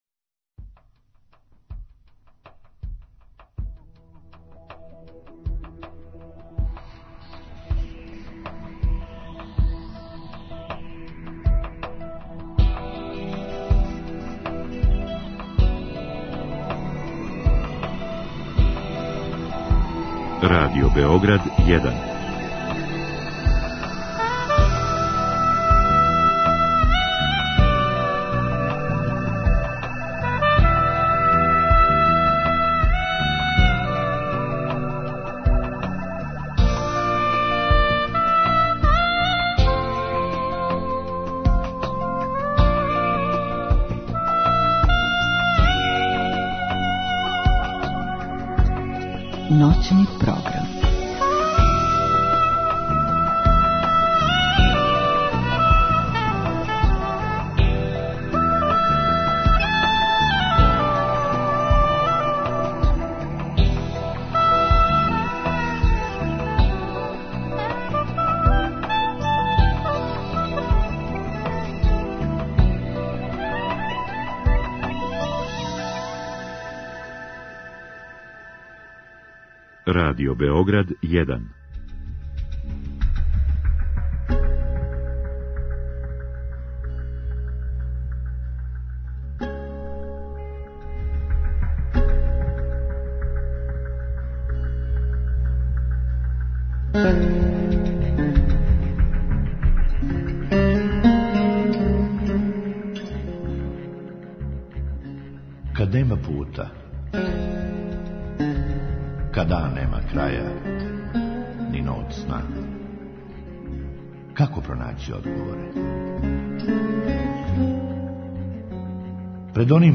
У другом сату дајемо прилику нашим слушаоцима да поделе са нама своје начине на које превладавају животне проблеме i дилеме.